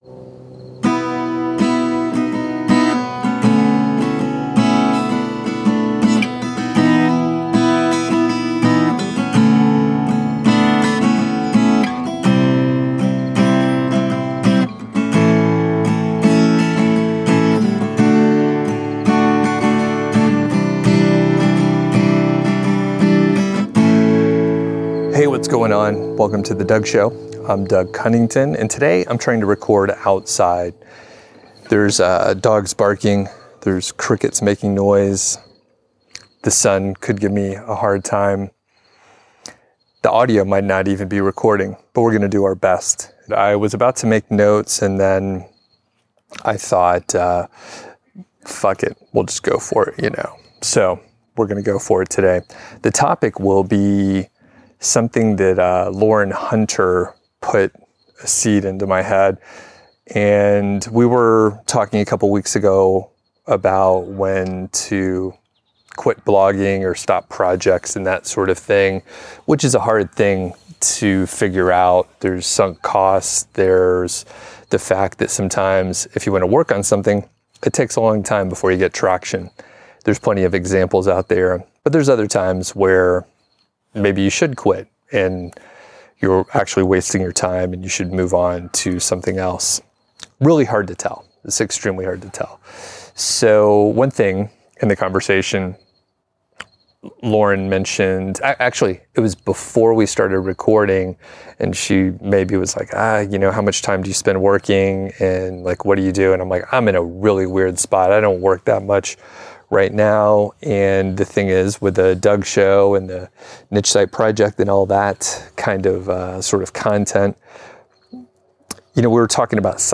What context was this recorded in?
Introduction and Outdoor Recording Challenges